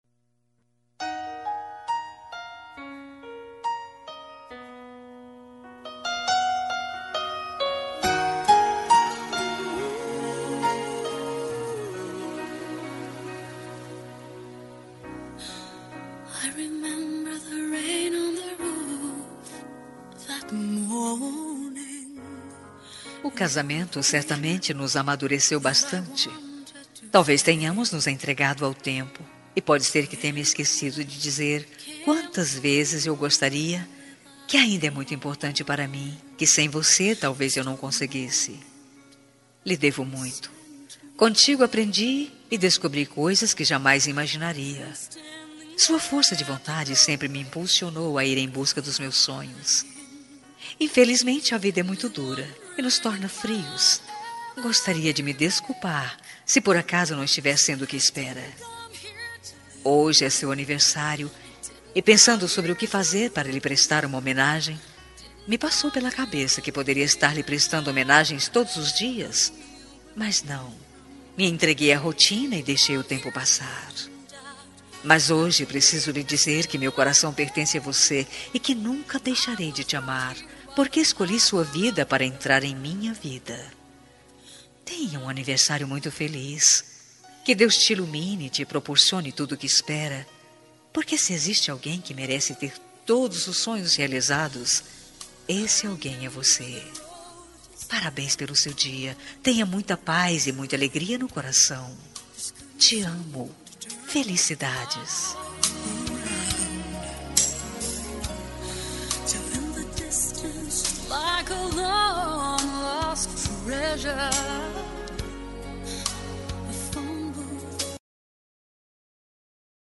Aniversário Marido – Voz Feminina – Cód: 350330